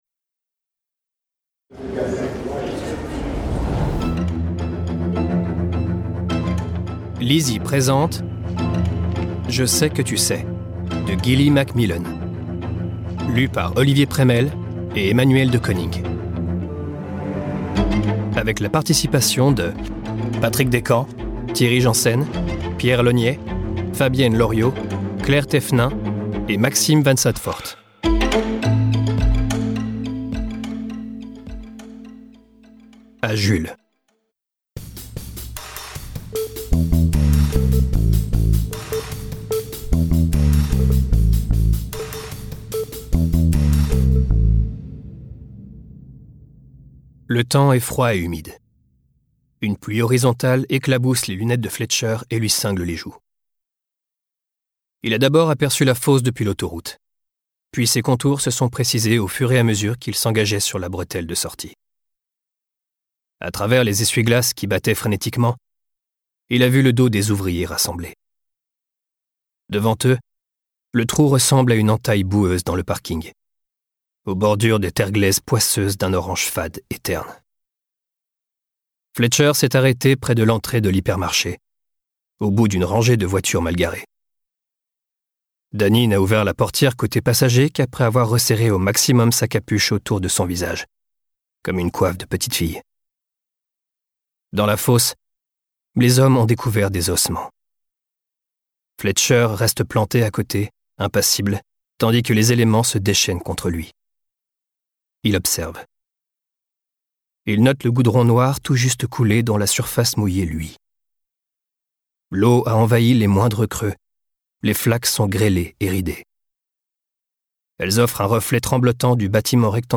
je découvre un extrait - Je sais que tu sais de Gilly MACMILLAN